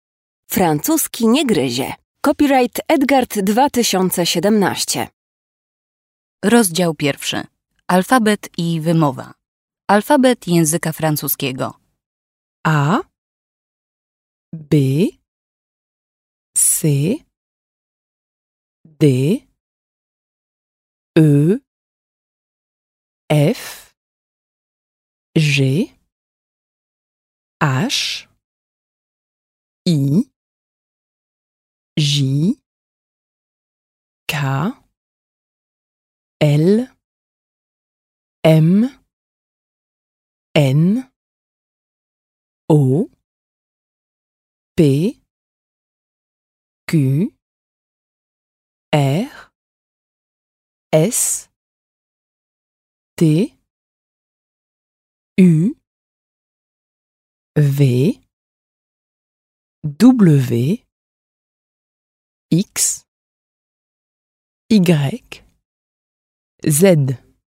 Posłuchaj, jak wymawiać poszczególne litery francuskiego alfabetu.
Nagranie alfabetu
Francuski_nie_gryzie_rozdzial_01_alfabet.mp3